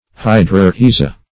Search Result for " hydrorhiza" : The Collaborative International Dictionary of English v.0.48: Hydrorhiza \Hy`dro*rhi"za\, n.; pl.